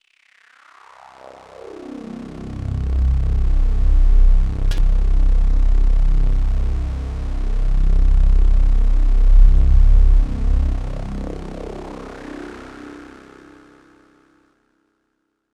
61PAD 01.wav